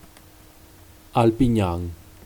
Alpignano (Italian: [alpiɲˈɲaːno]; Piedmontese: Alpignan [alpiˈɲɑŋ]
Pms-Alpignan.oga.mp3